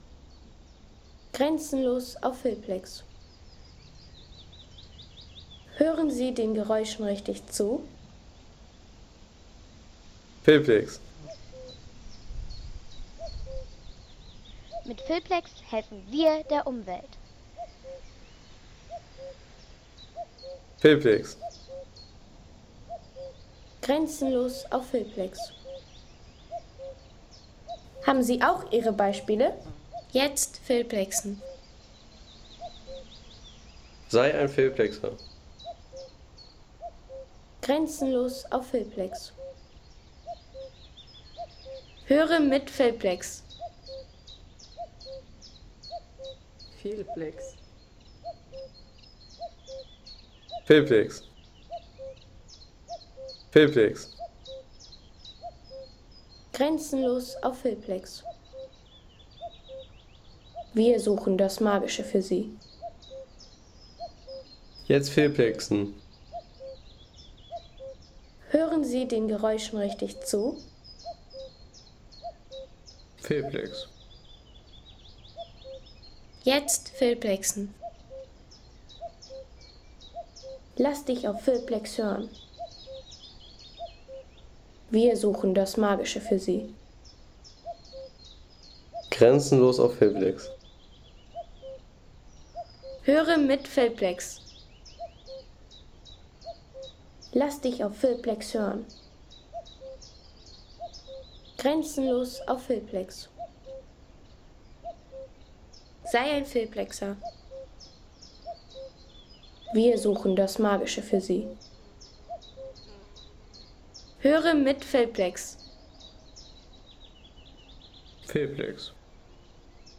Kuckuck im Moorgebiet
Der Kuckuck im Moorgebiet bei Aabenraa.